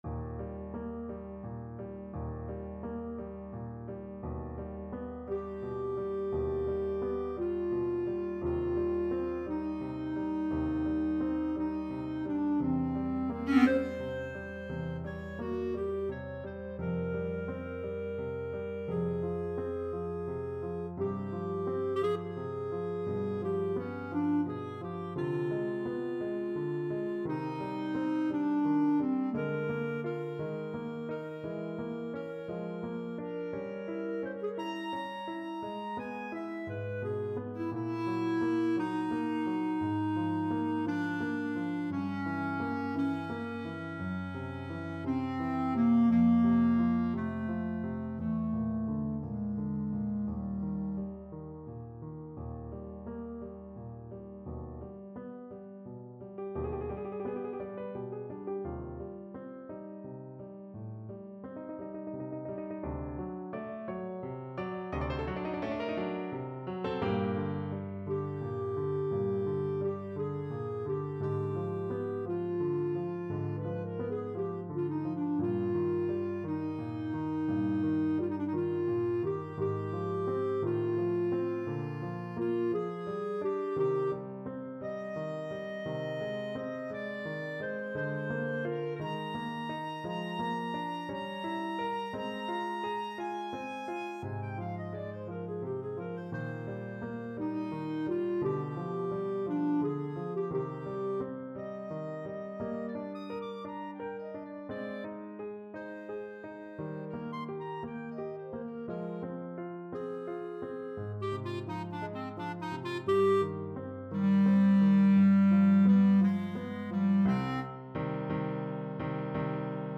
6/8 (View more 6/8 Music)
Classical (View more Classical Clarinet Music)